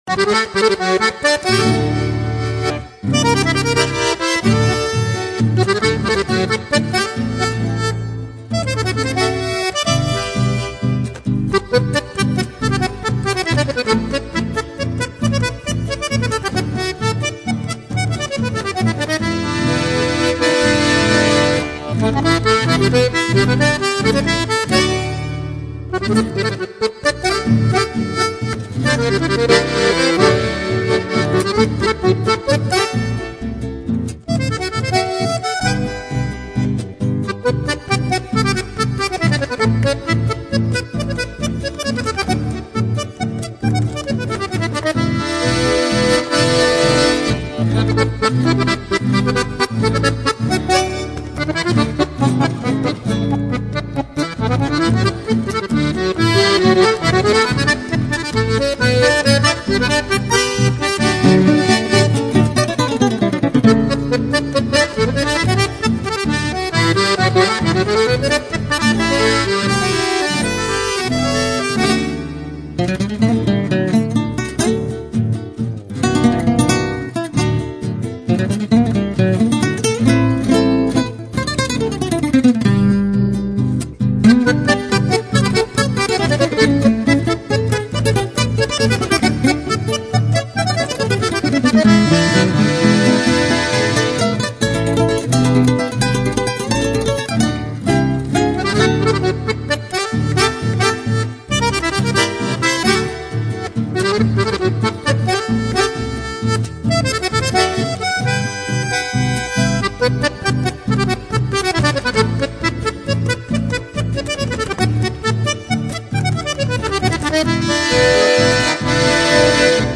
Chamamé